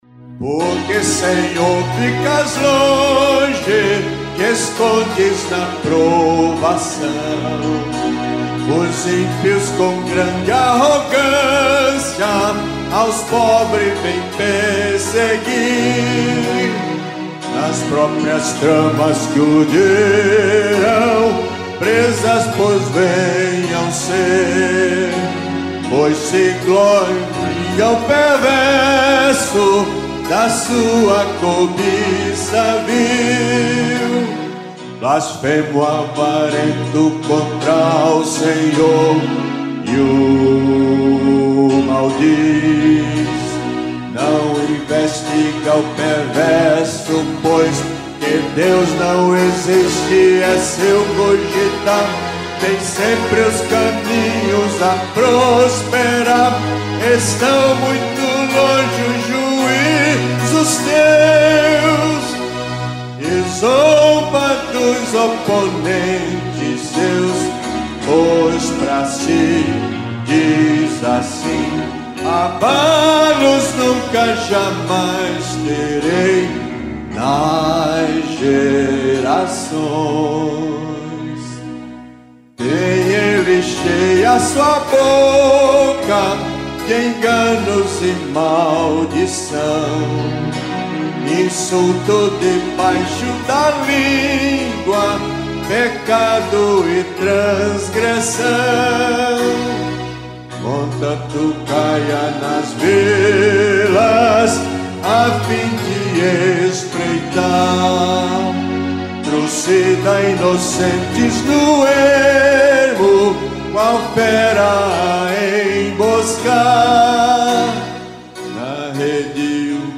salmo_10B_cantado.mp3